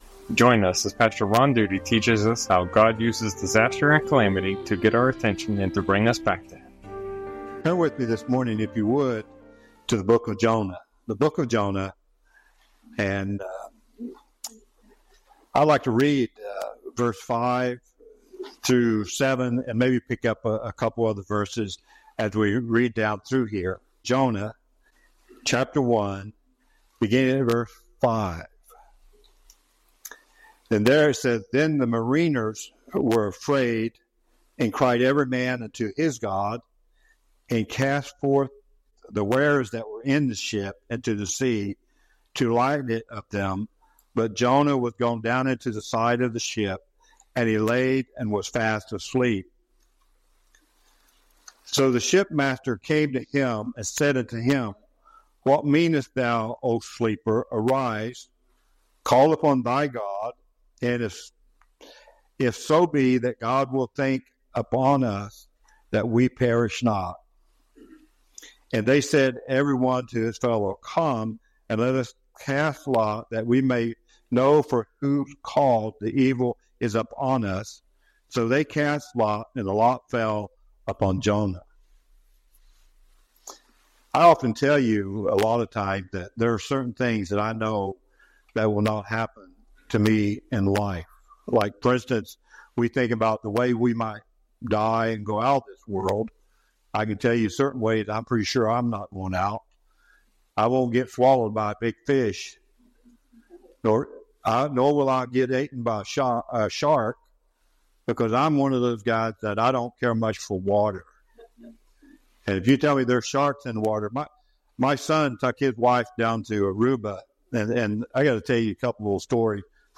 Sermons | First Baptist Church of Carroll